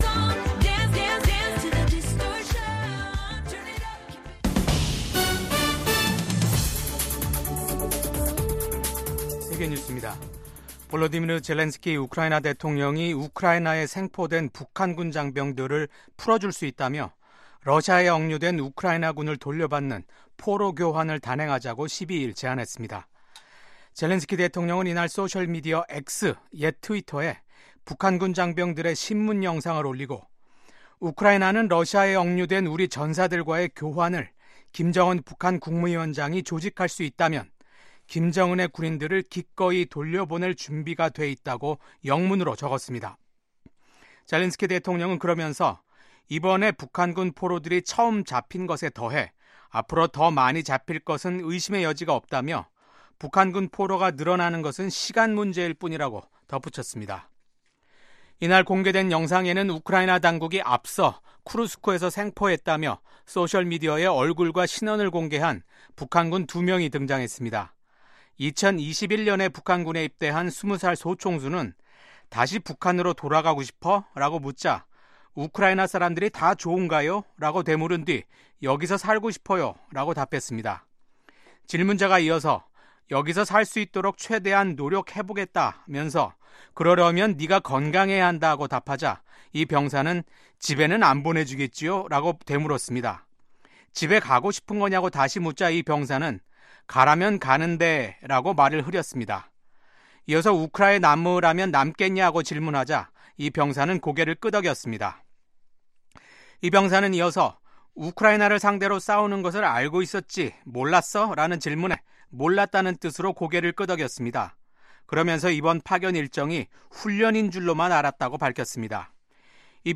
VOA 한국어 아침 뉴스 프로그램 '워싱턴 뉴스 광장'입니다. 러시아 서부 쿠르스크 지역에서 20살과 26살인 북한 군인 2명이 생포됐습니다.